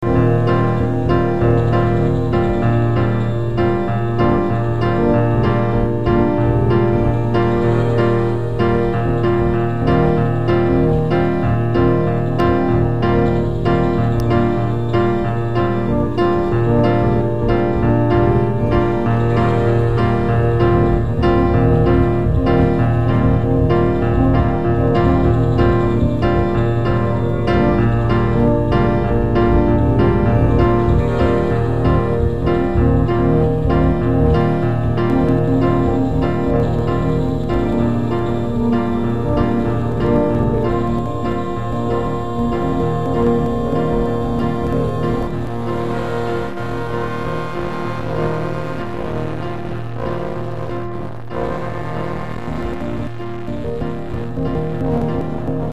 Catalogado quase sempre como pós-rock